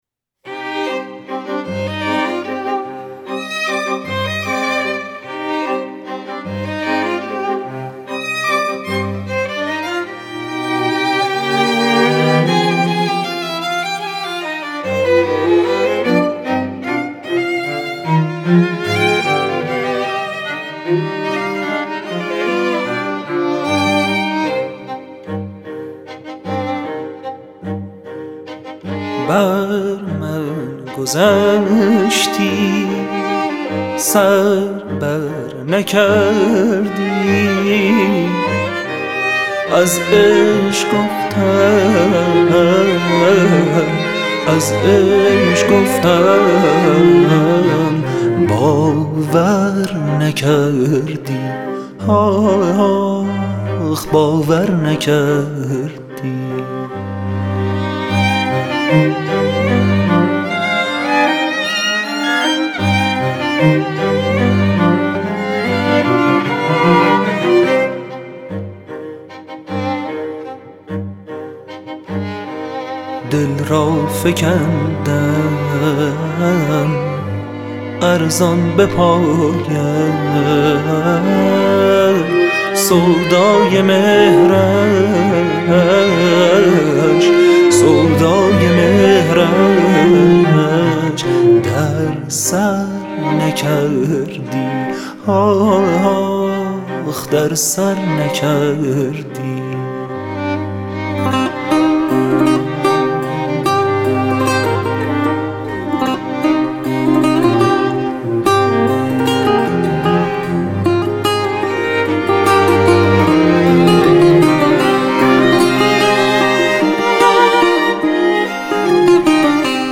ویولن یک
ویولن دو
ویولن آلتو
ویولن سل
تصنیف